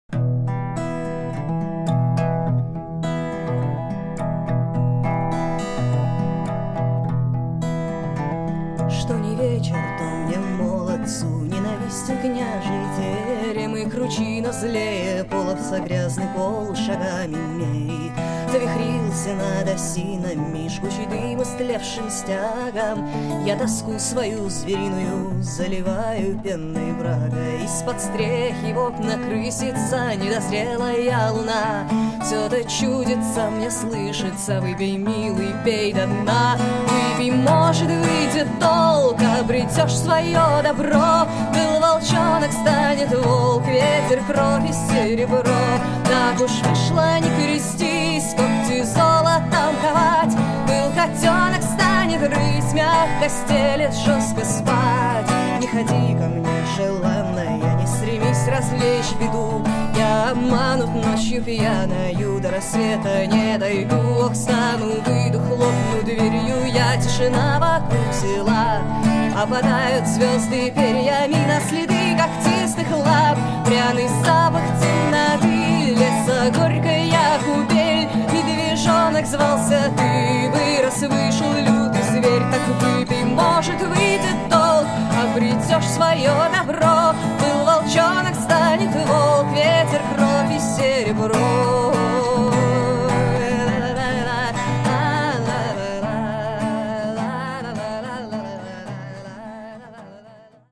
Фолк. Соло под гитару.